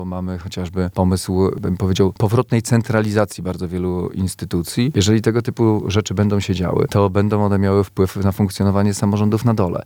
– mówi marszałek województwa Olgierd Geblewicz.